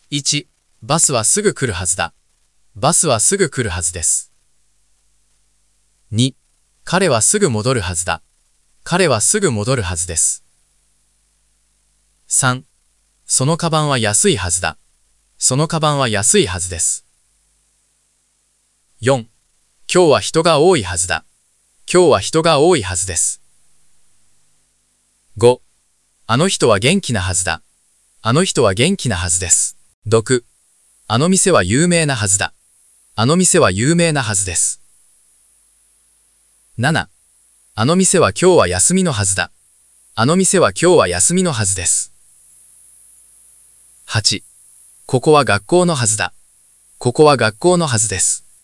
戻る【もど＼る /Modoru/】 to return go back
有名【ゆうめい￣ /Yūmei/】 famous
休み【やすみ￣ /Yasumi/】 holiday day off